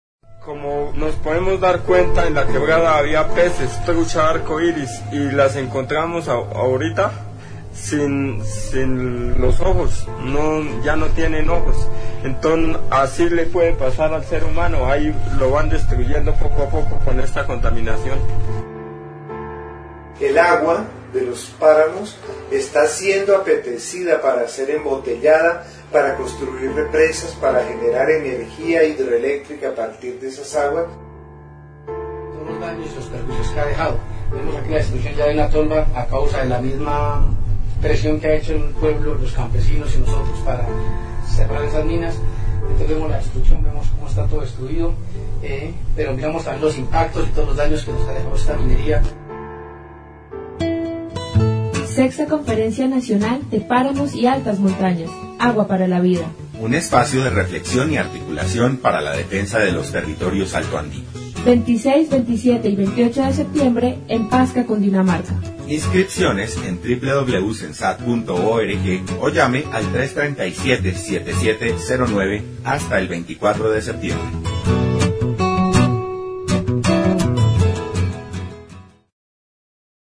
Piezas radiales adjuntas en mp3
promo_3.mp3